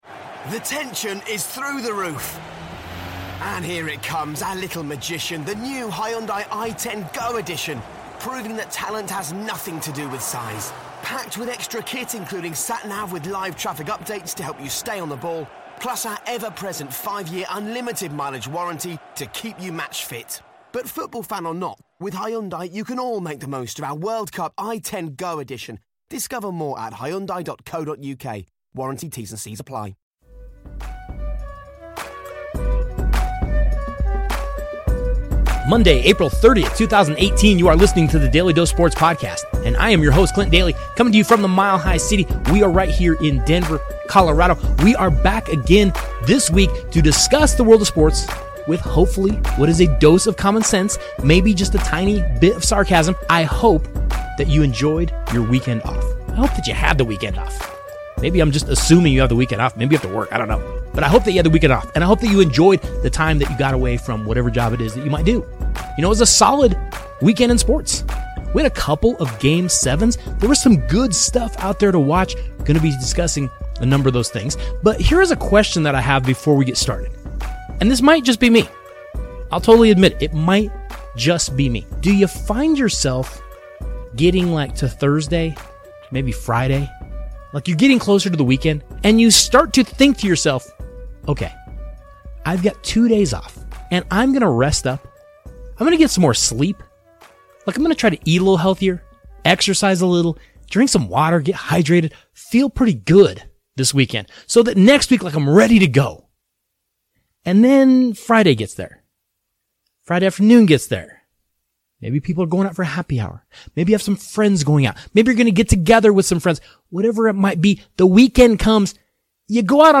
A long time friend of the show stops by to discuss the NFL Draft.